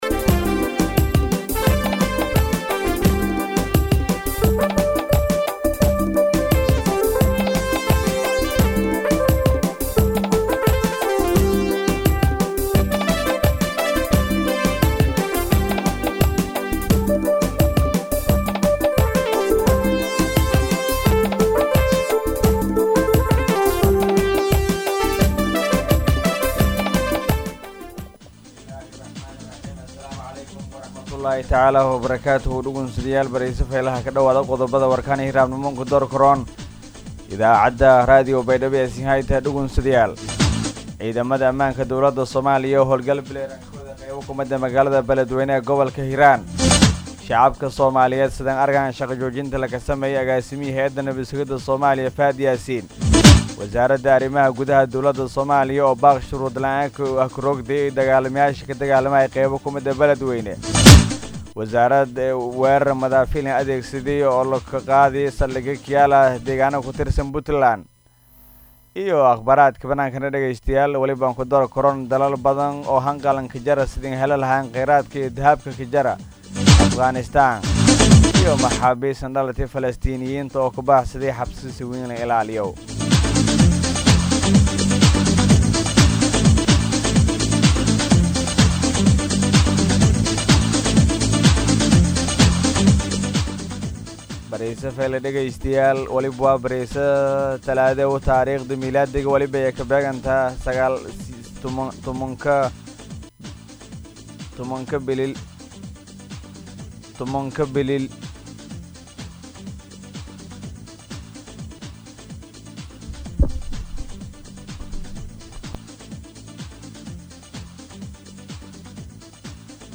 DHAGEYSO:-Warka Subaxnimo Radio Baidoa 7-9-2021